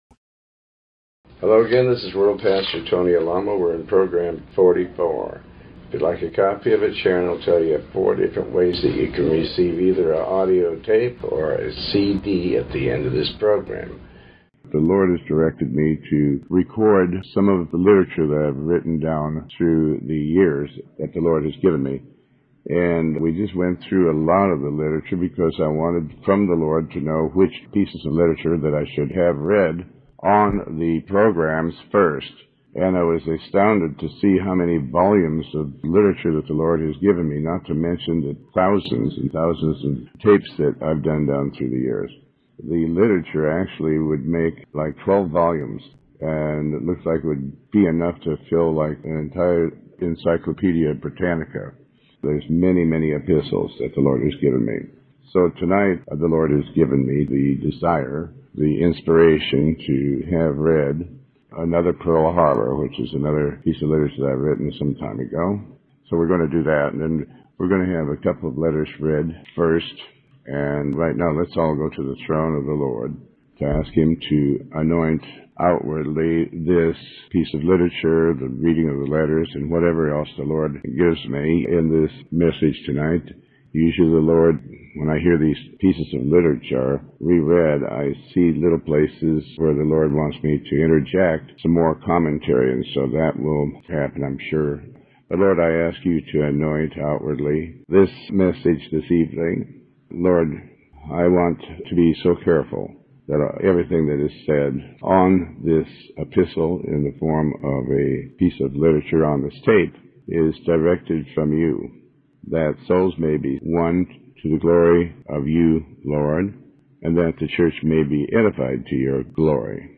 Show Headline Tony Alamo Show Sub Headline Pastor Tony Alamo Another Pearl Harbor Part 1 Tony Alamo with Tony Alamo World Wide Ministries Pastor Tony Alamo Another Pearl Harbor Part 1 #044 In this program (which originally aired in 2005,) Pastor Alamo reads, and comments on a piece of Gospel literature that he wrote entitled, "Another Pearl Harbor." This is an exposé regarding the end times.